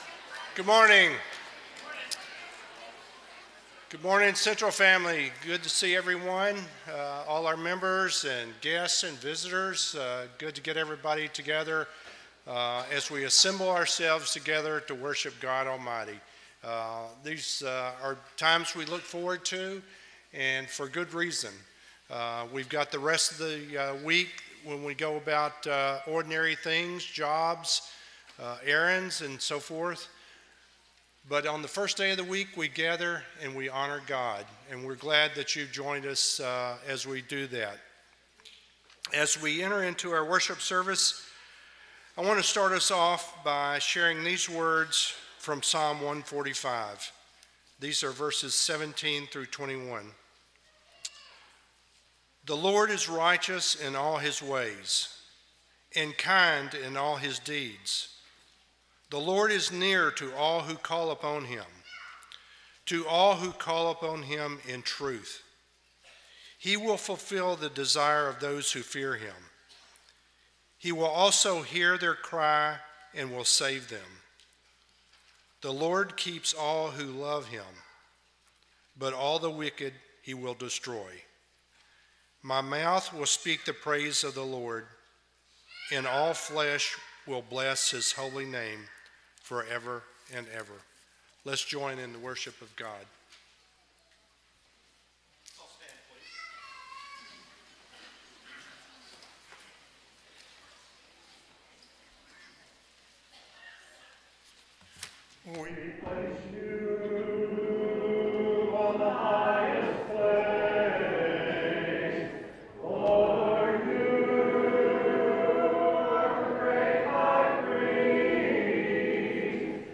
Ephesians 4:7, English Standard Version Series: Sunday AM Service